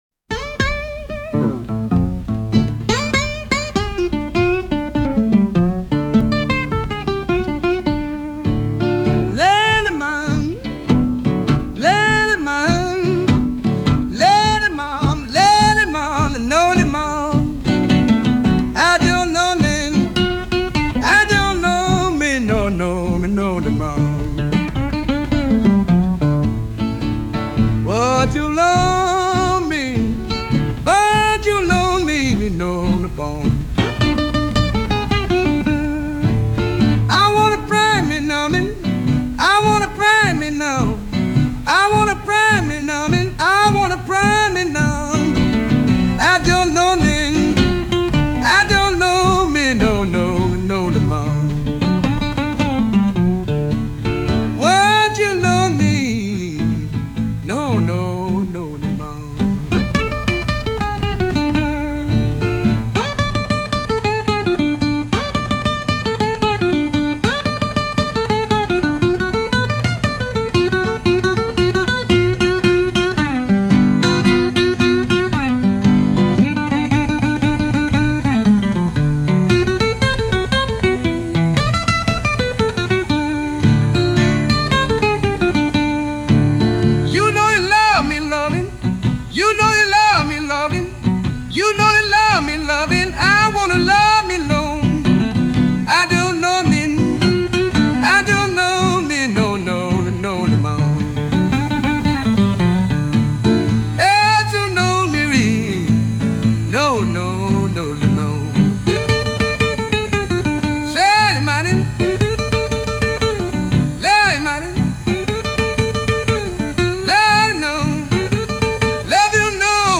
Instrumental - Real Liberty Media Dot Com- 4 mins.mp3